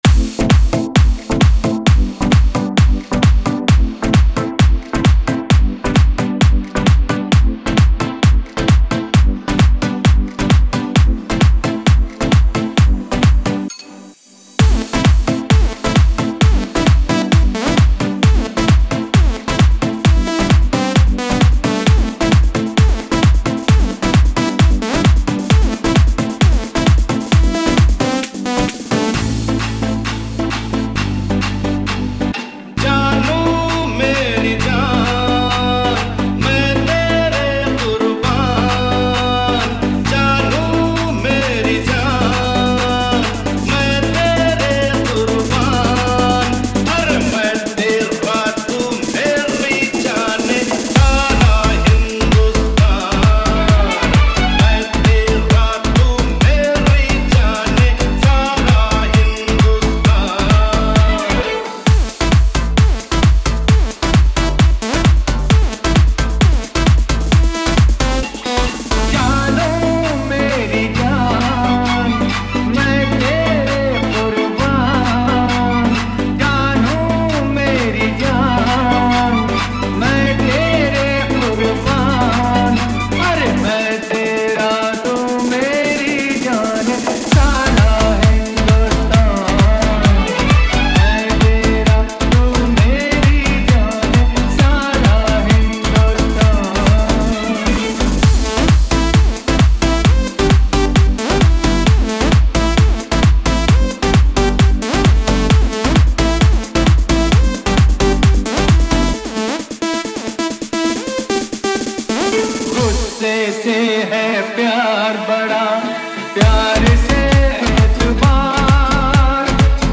wedding remix